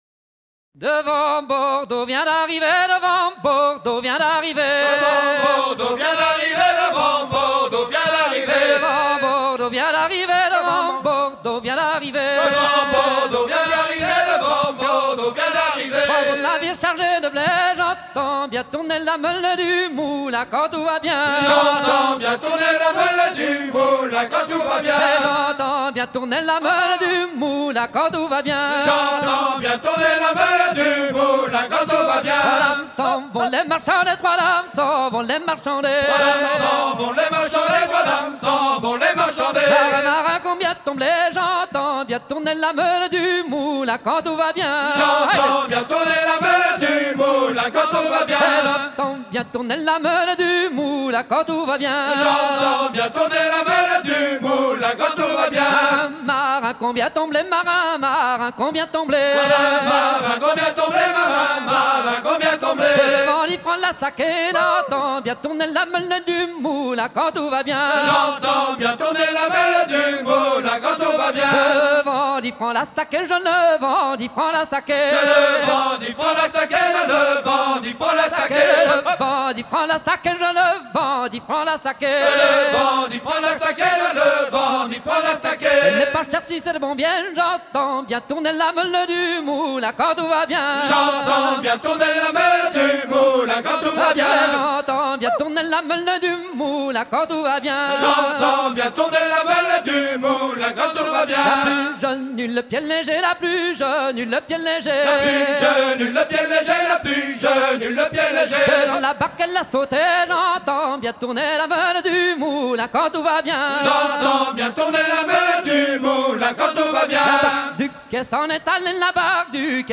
danse : laridé, ridée